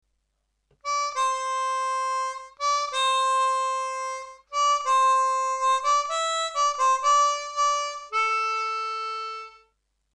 C Major Chromatic (Hohner CX12)
End section